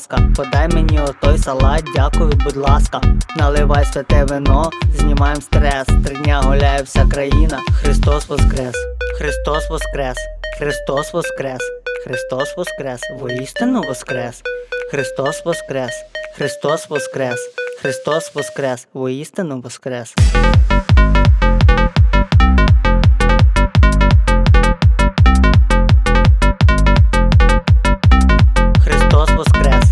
Жанр: Танцевальные / Украинские